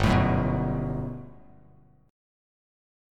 GM9 Chord
Listen to GM9 strummed